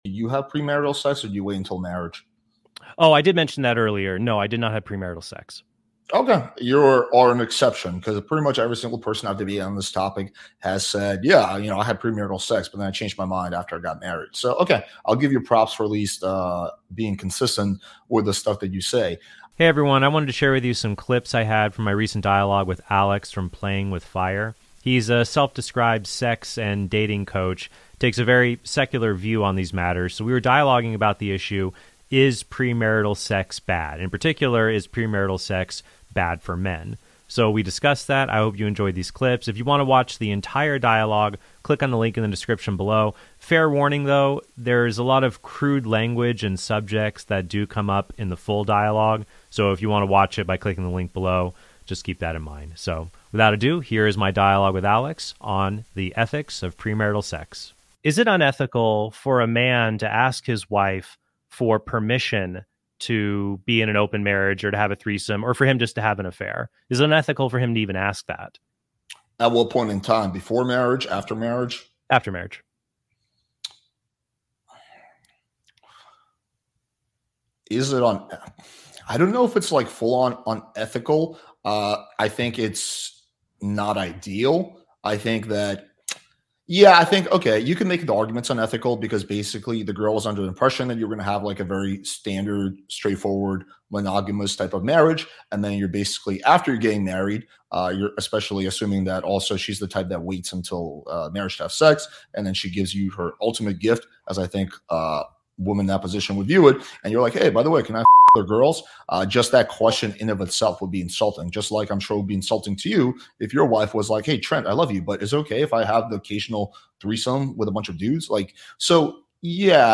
DIALOGUE: Is Premarital Sex Bad?